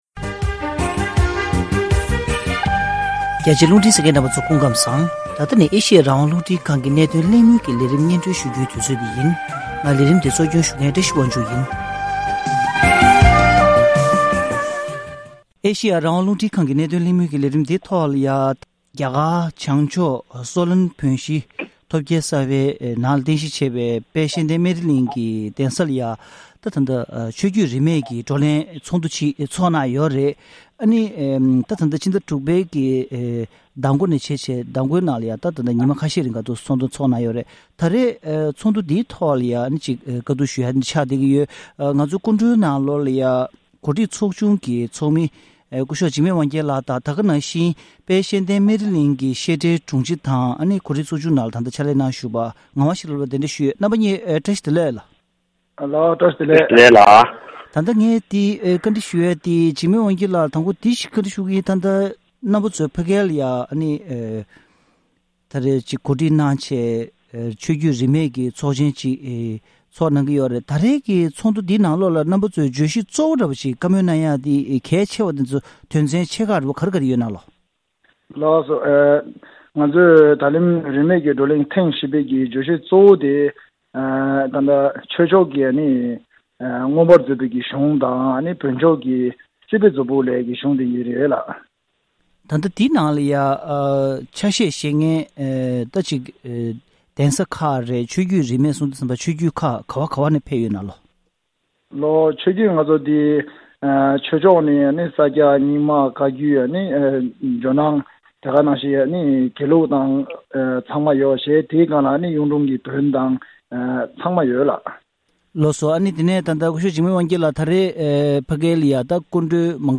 གླེང་མོལ་ཞུས་པར་གསན་རོགས་གནང་།།